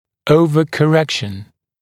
[ˌəuvəkə’rekʃn][ˌоувэкэ’рэкшн]гиперкоррекция, избыточная коррекция
overcorrection.mp3